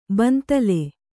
♪ bantale